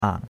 ang3.mp3